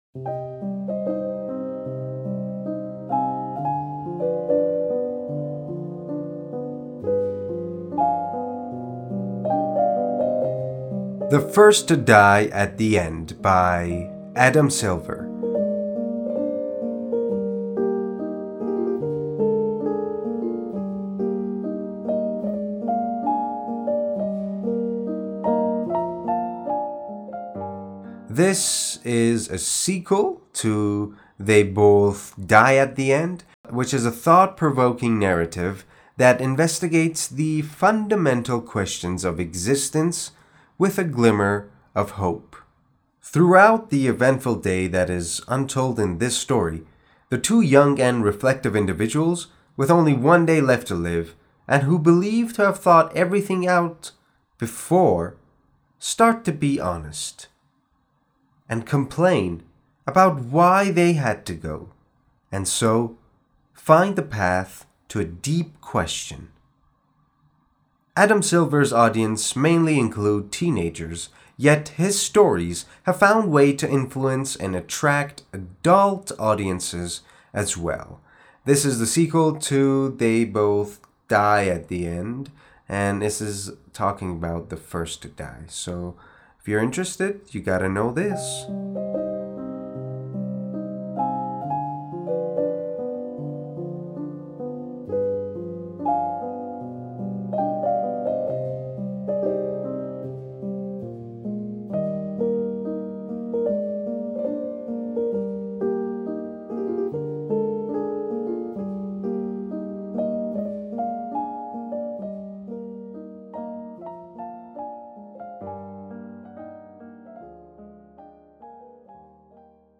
معرفی صوتی کتاب The First to Die at The End